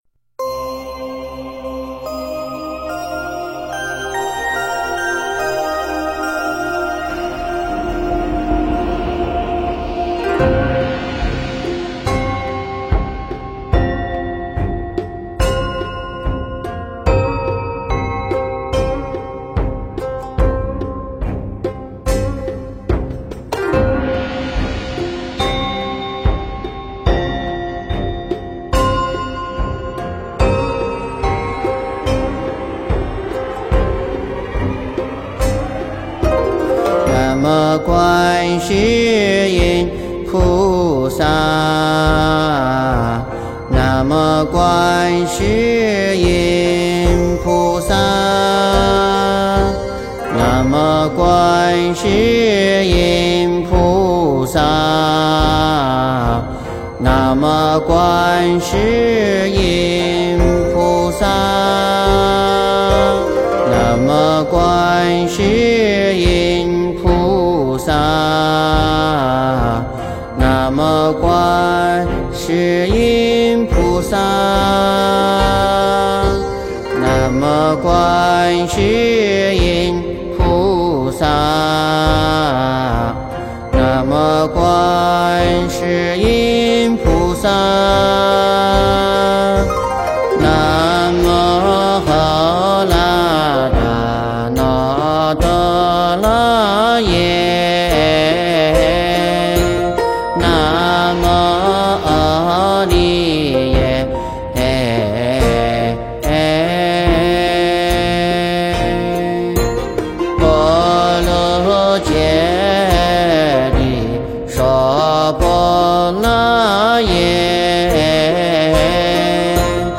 诵经
佛音 诵经 佛教音乐 返回列表 上一篇： 地藏赞 下一篇： 观音灵感真言 相关文章 貧僧有話11說：贫僧受难记--释星云 貧僧有話11說：贫僧受难记--释星云...